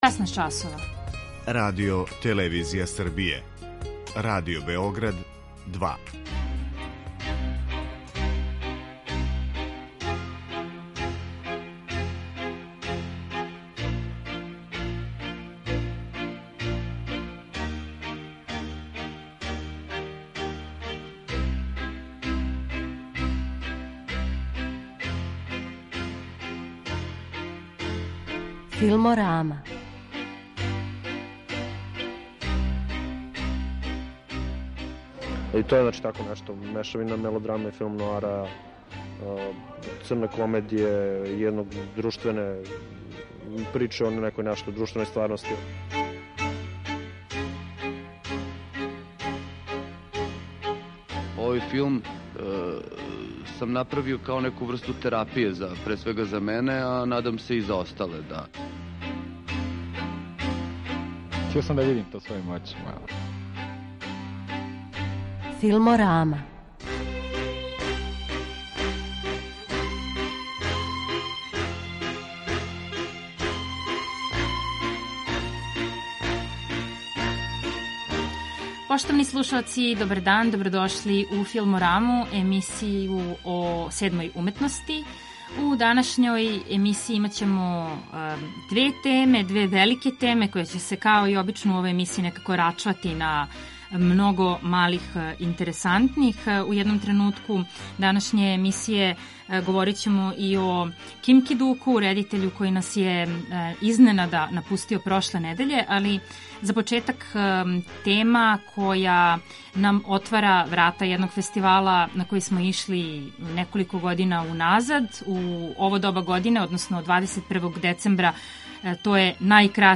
У данашњој емисији чућемо неке наше писце, редитеље и новинаре који су били љубитељи Ким Ки-дуковог рада.